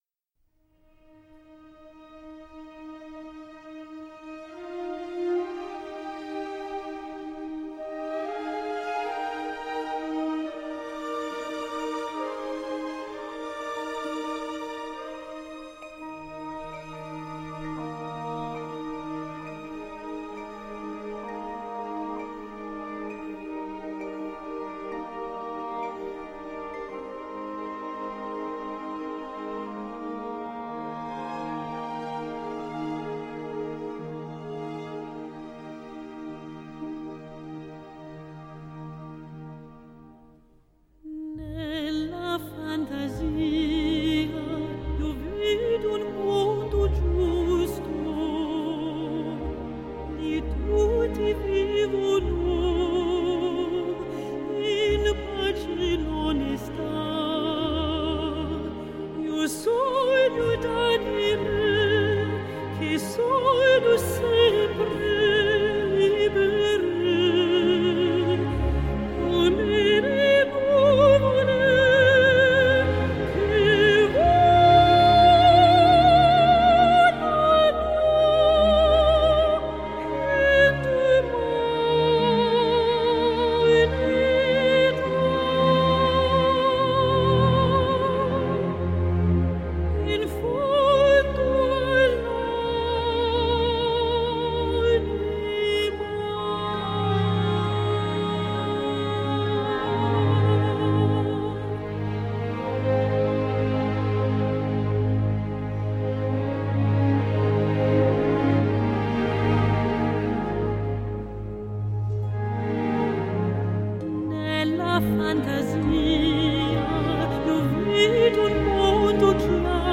【跨界經典】優雅圓潤的美聲